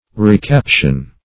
Recaption \Re*cap"tion\ (r[-e]*k[a^]p"sh[u^]n), n. (Law)